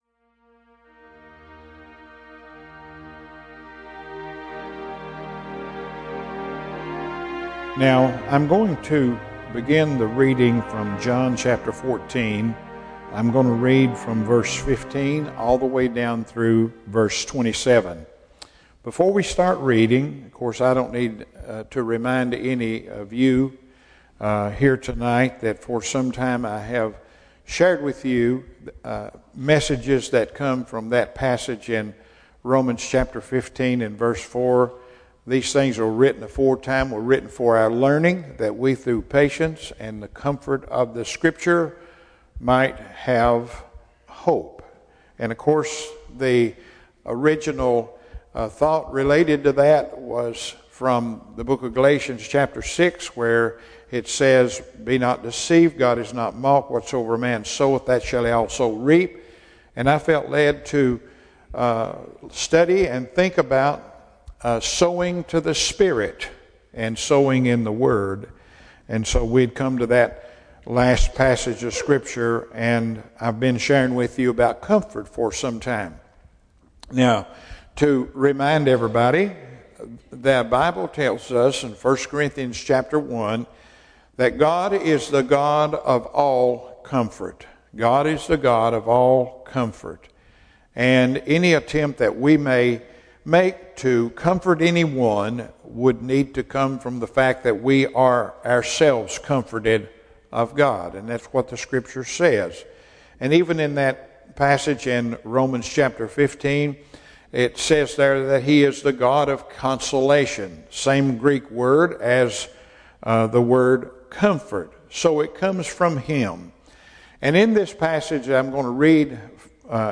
Message From Emmanuel is a weekly audio ministry of Emmanuel Baptist Church in Irvine, KY.
Service Type: Evening Service